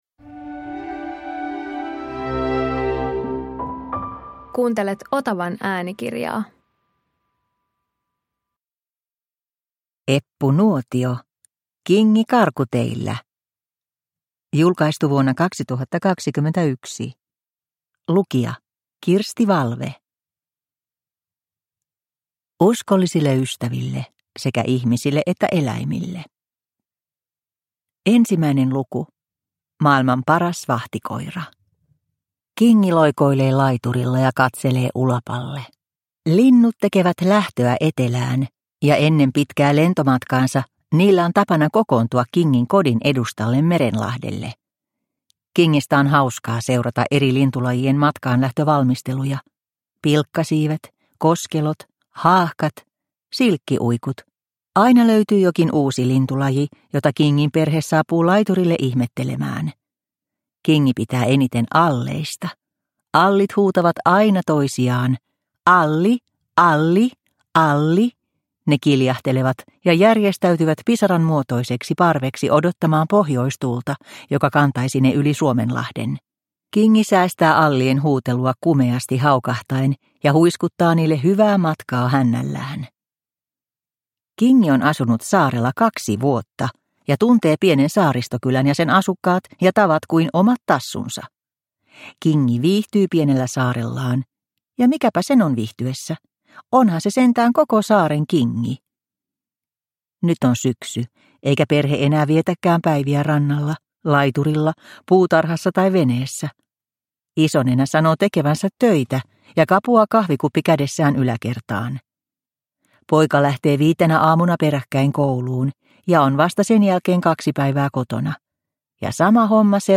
Kingi karkuteillä – Ljudbok – Laddas ner